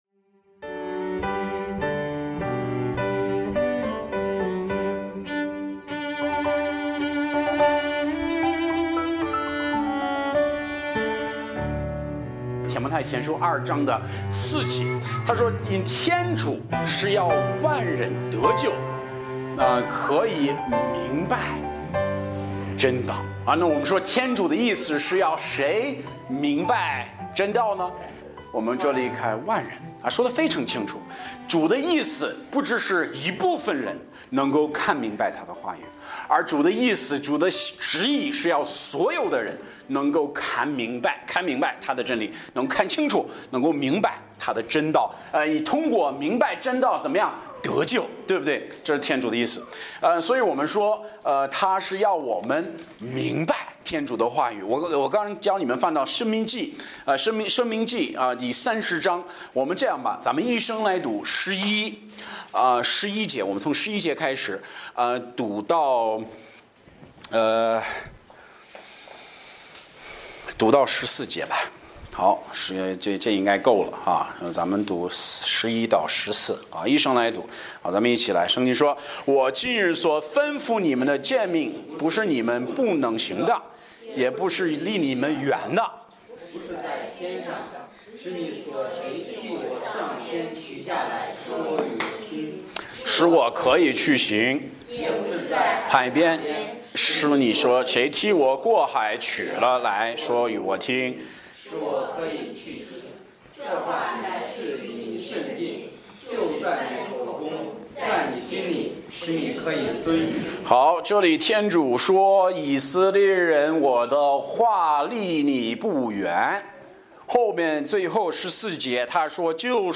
讲道者